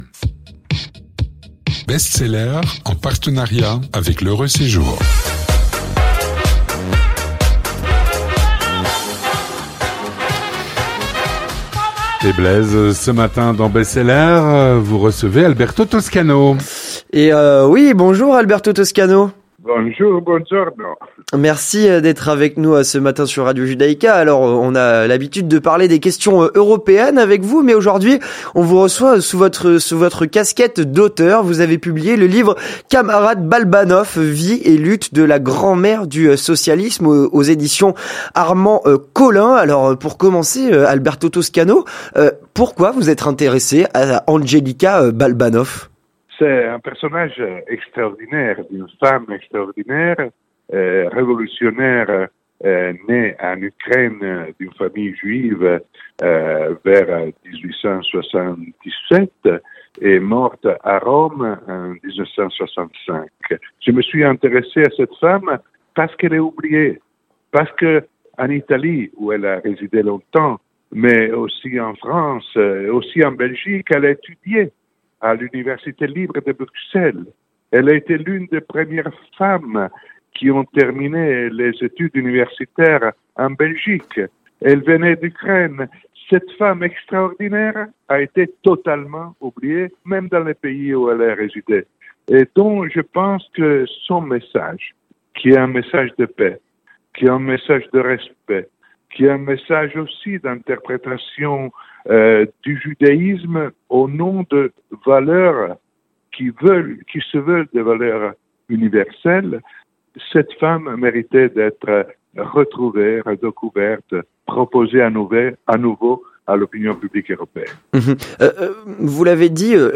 Présentation du livre